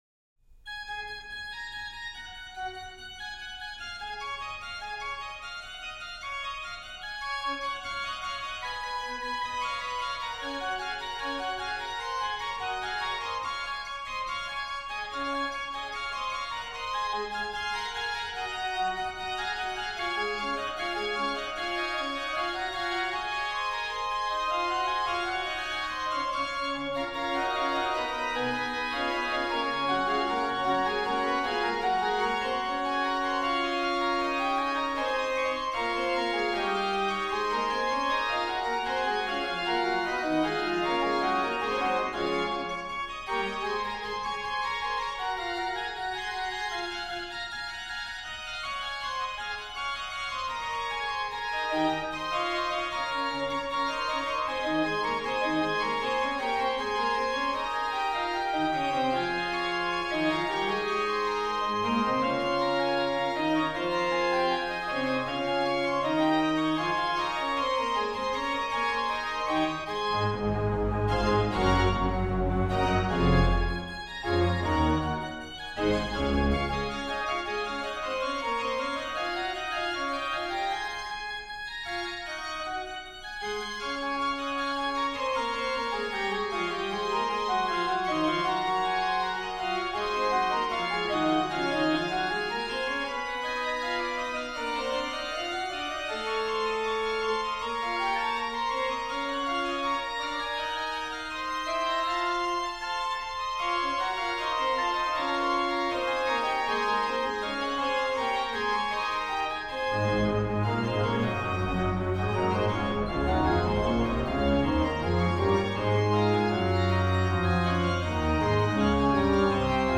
PED: Sub16, Tr8, MAN/PED
m. 71: PED: +Viol16